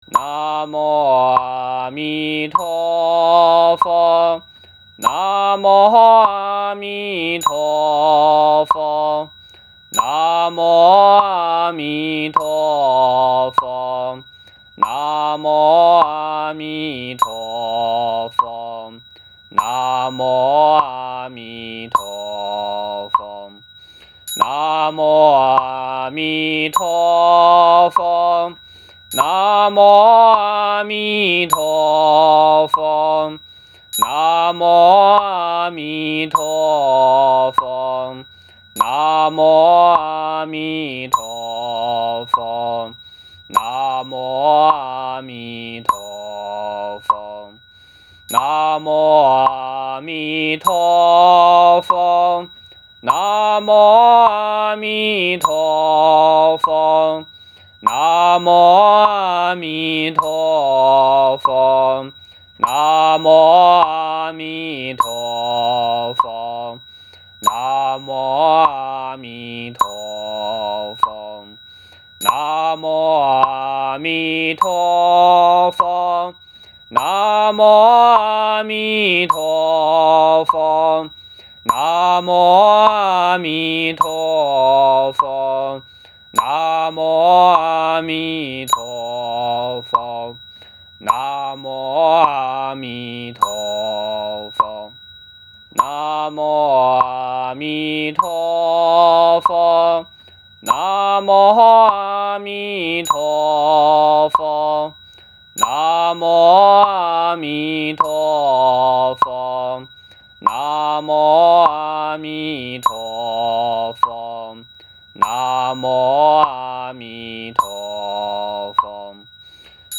Cantos y recitaciones – 海辉山
Versión acelerada de cuatro caracteres, cuatro entonaciones